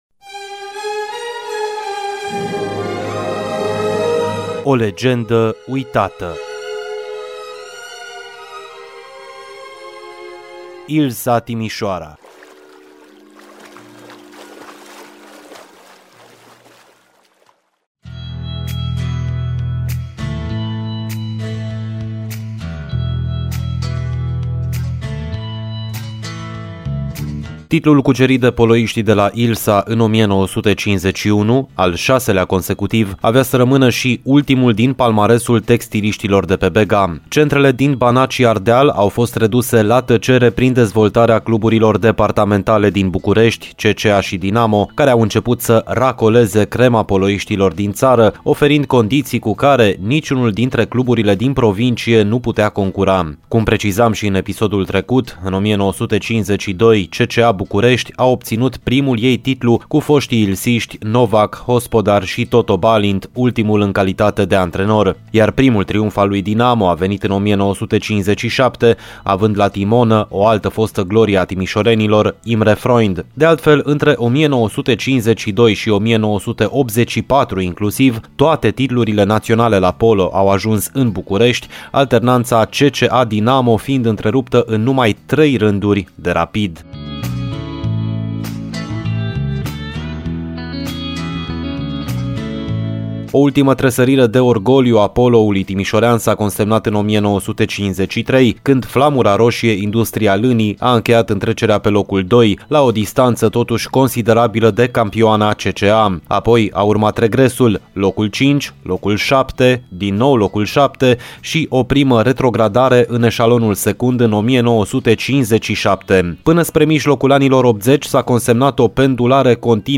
și difuzat în ediția de astăzi a emisiunii Arena Radio: